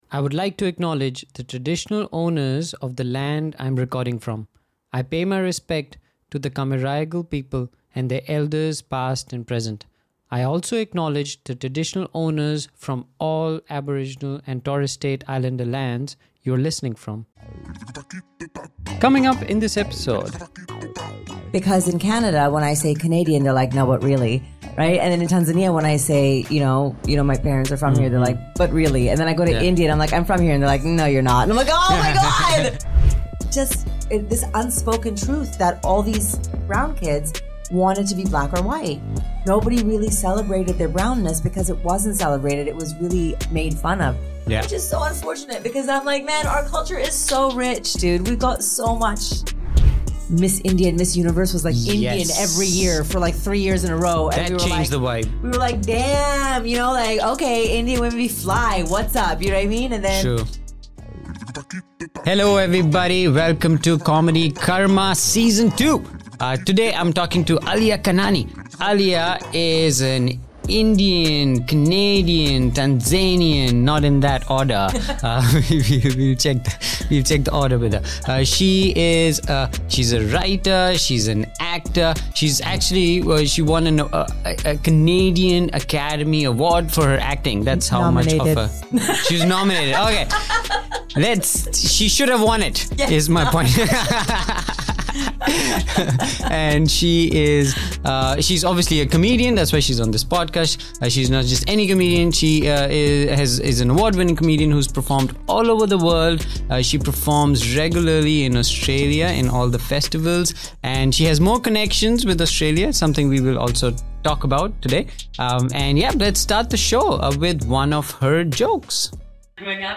They’ll also laugh through the hilarious stories behind her punchlines - from what it’s like to travel with a Muslim name, to navigating the world as a brown traveller, and her parent's reaction to her comedy career.